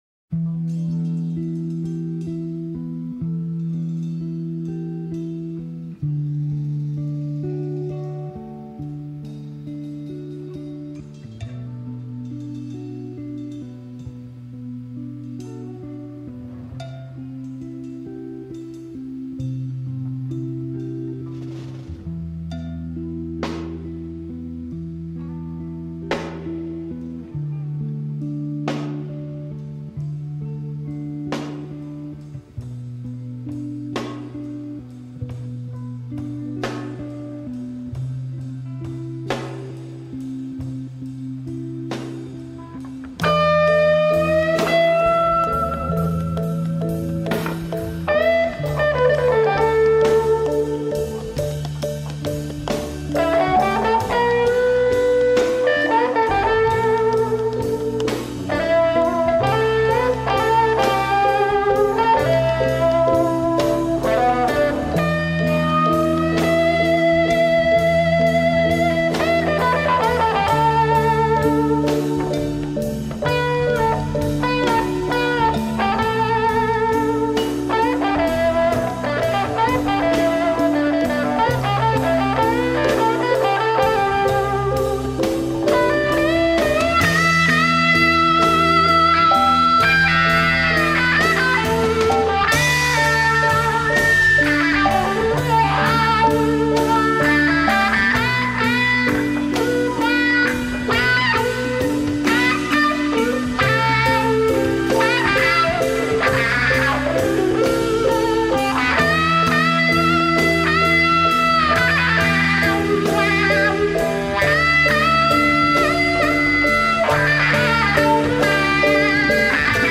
آلبوم کلاسیک فانک/سایکدلیک
صدای گیتار ۱۰ دقیقه‌ای